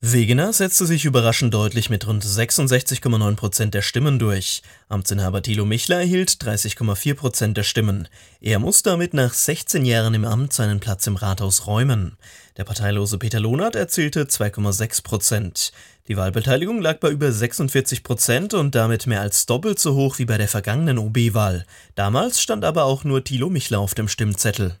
Patrick Wegener, neuer Oberbürgermeister von Öhringen